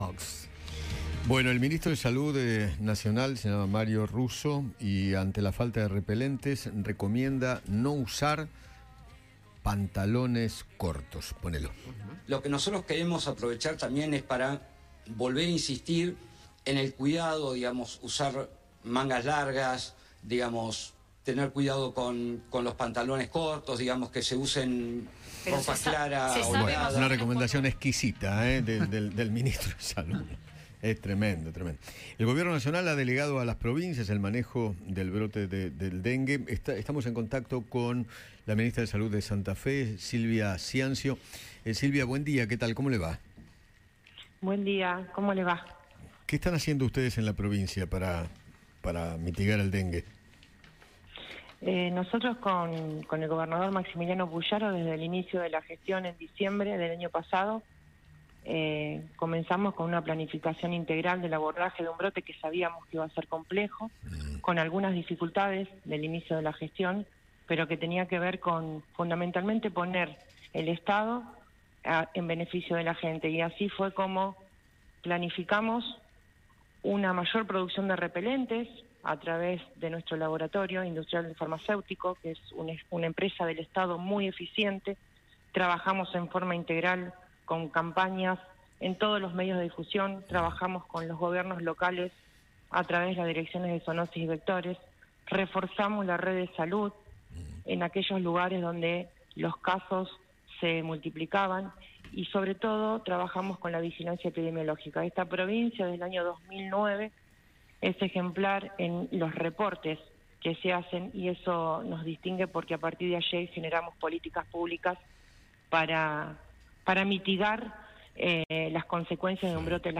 Silvia Ciancio, la ministra de Salud de Santa Fe, dialogó con Eduardo Feinmann sobre las recomendaciones de Mario Russo ante el brote del dengue y la falta de repelentes.